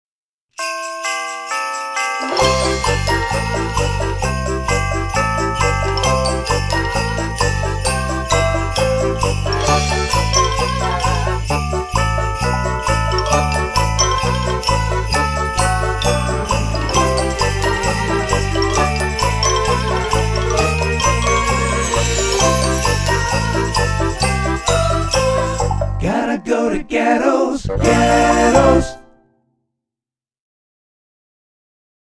custom music score
listen to score)